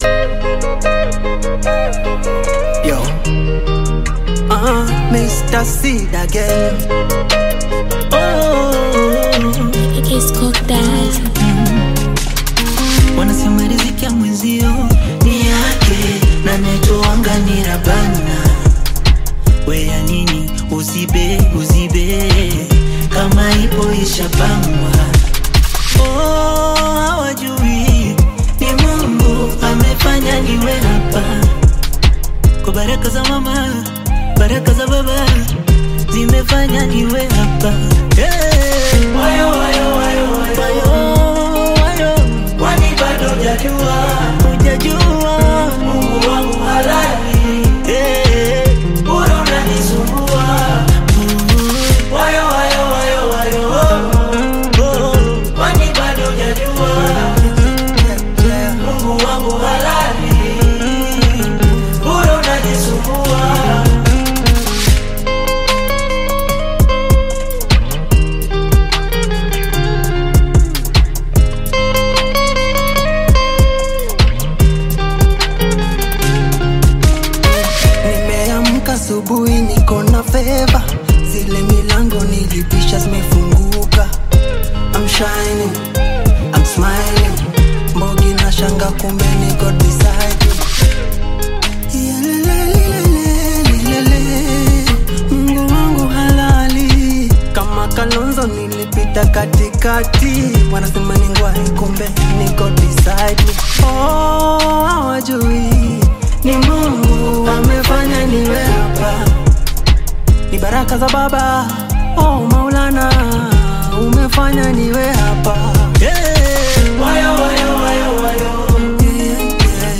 delivers smooth and heartfelt vocals